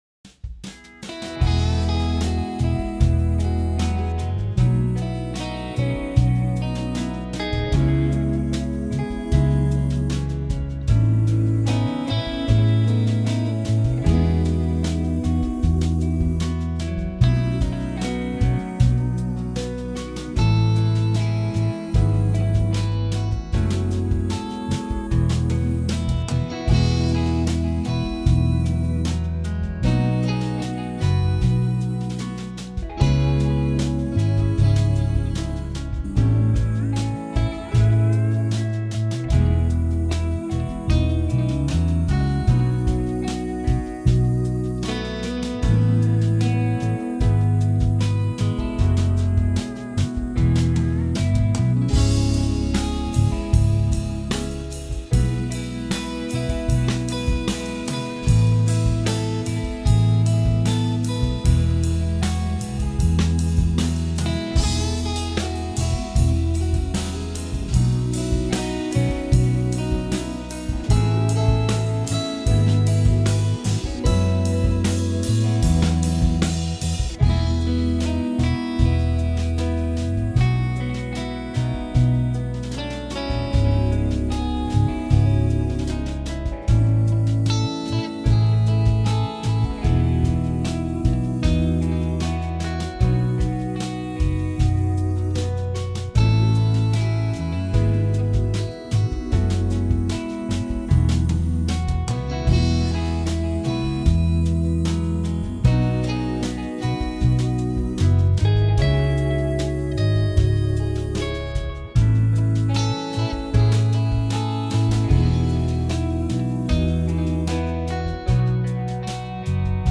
Home > Music > Blues > Smooth > Medium > Floating